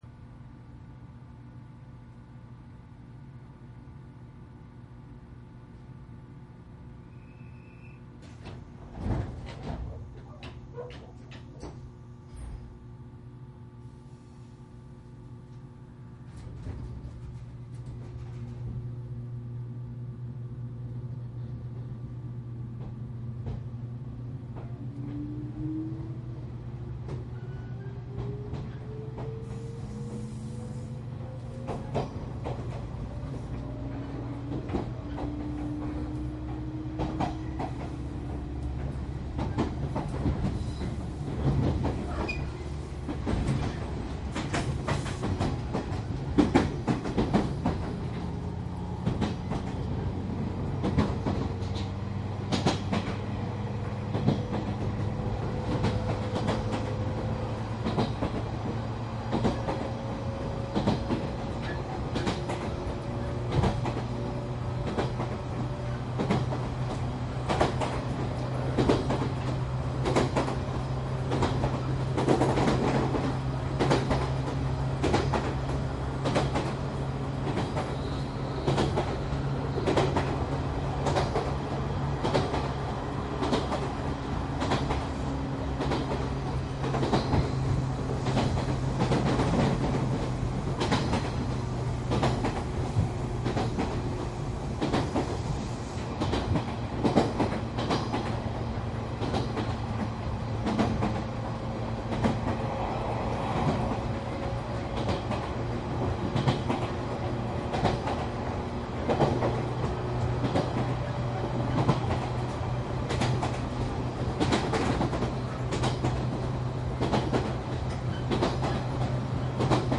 日豊線475系/717系走行音 ＣＤ♪
九州南部の日豊本線で475系急行型電車とその改造車717系録音したCDです。
■都城→隼人（川内ゆきですが途中の隼人まで） モハ474-31＜DATE05-1-24＞
サンプル音声 モハ474-31.mp3
マスター音源はデジタル44.1kHz16ビット（マイクＥＣＭ959）で、これを編集ソフトでＣＤに焼いたものです。